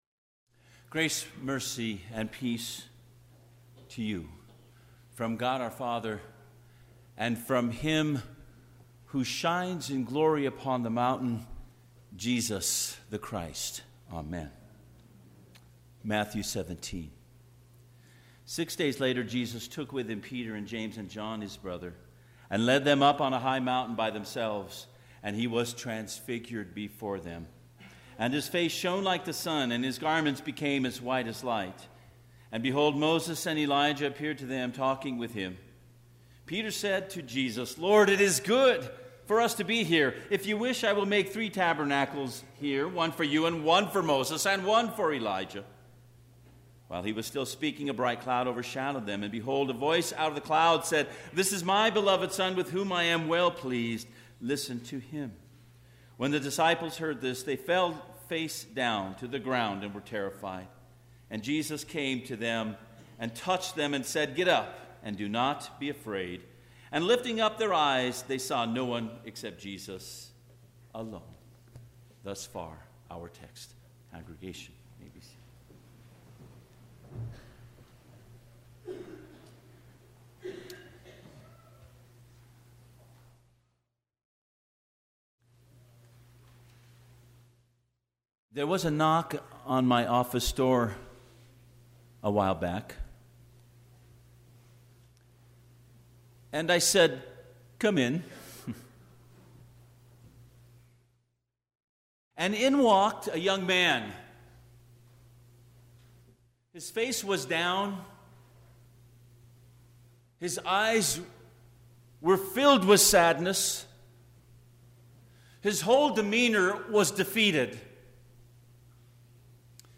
Sermon - 2/10/2019 - Wheat Ridge Lutheran Church, Wheat Ridge, Colorado